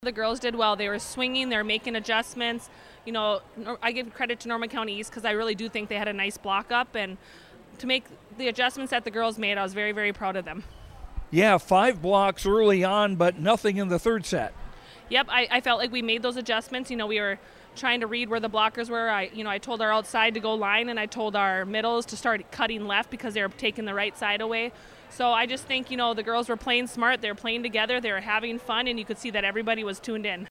Postgame comments